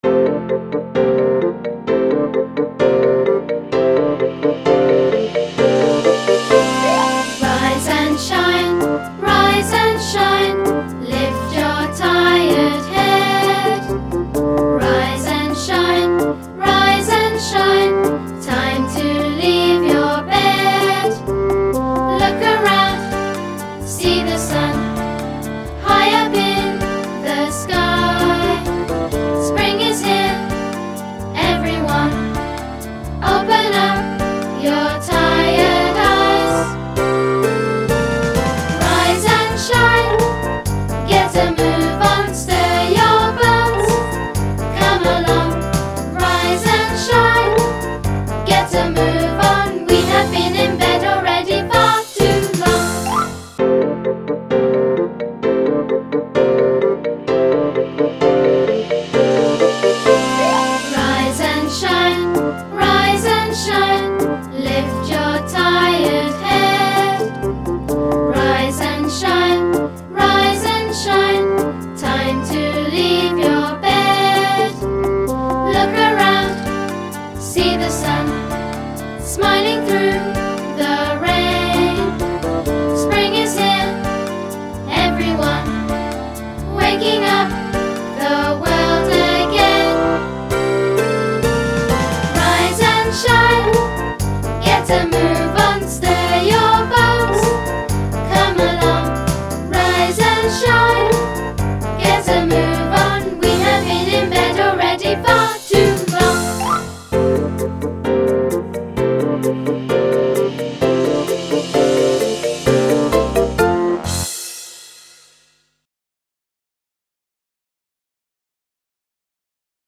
Song Vocal Track MP3 - click